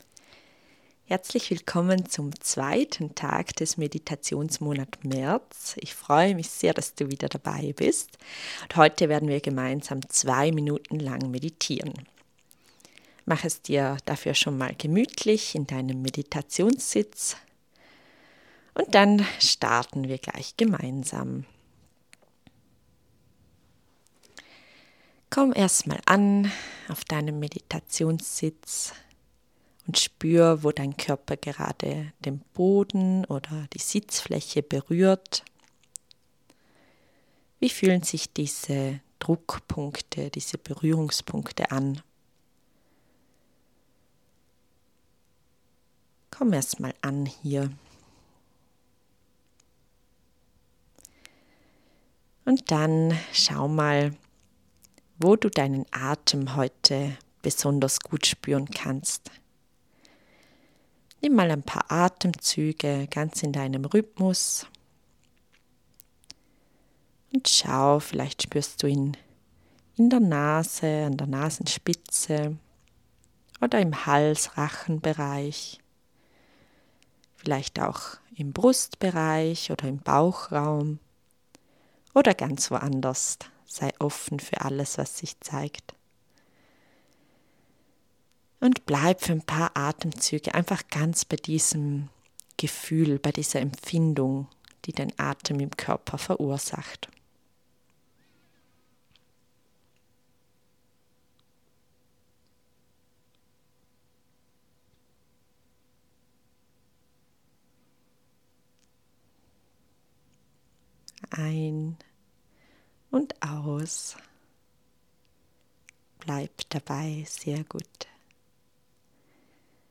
Deine Meditation